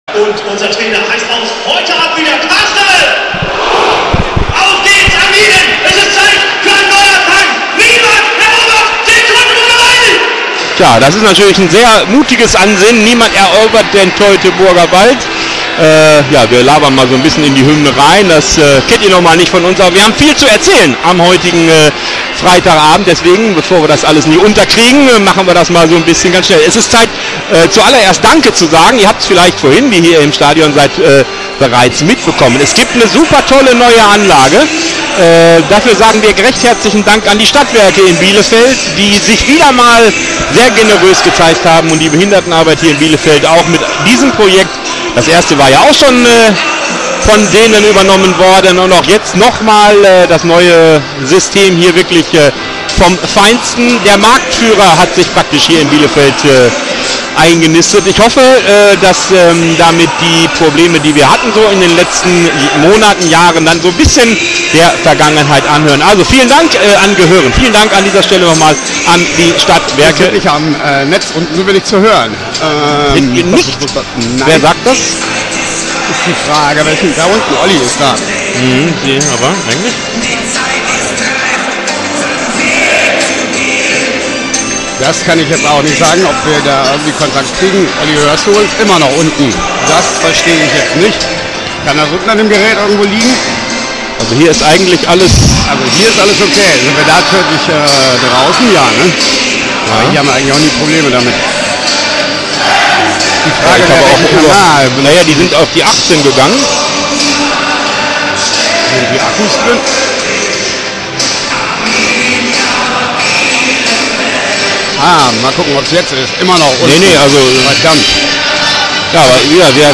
Die Spiel-Reportage im Player
Schüco Arena, Bielefeld